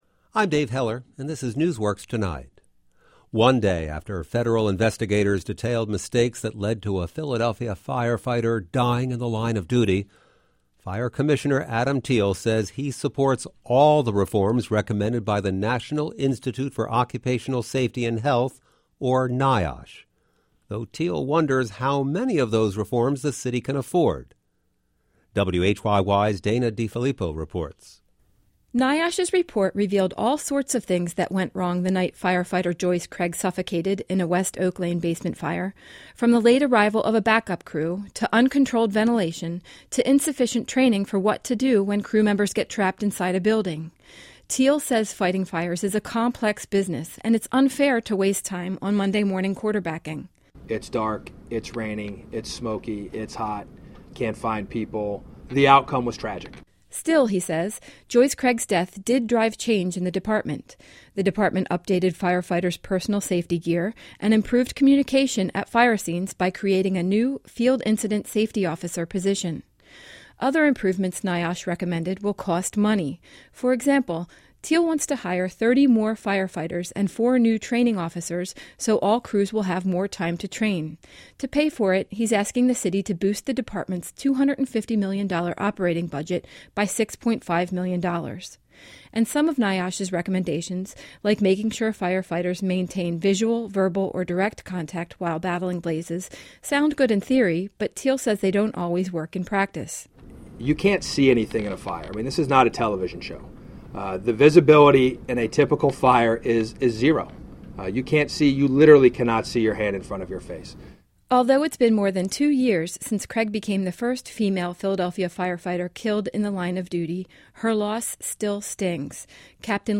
NewsWorks Tonight was a daily radio show and podcast that ran from 2011-2018.